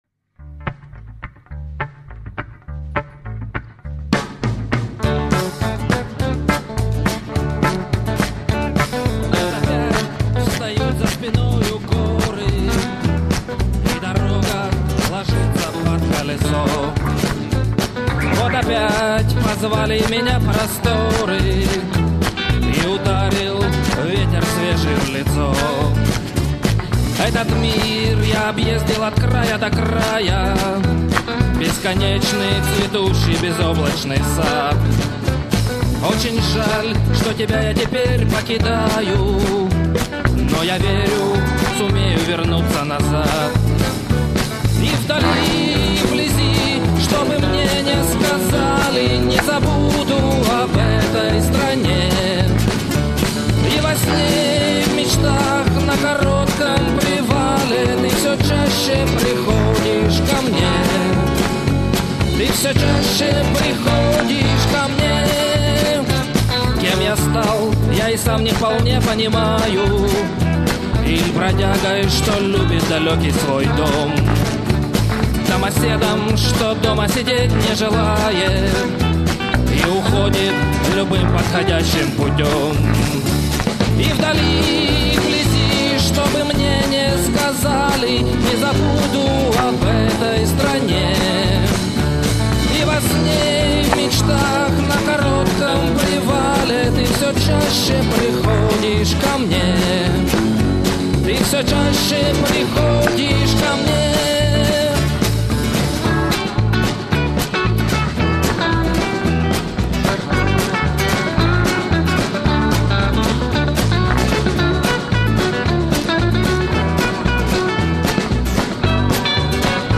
Песня из первой главы (живое исполнение):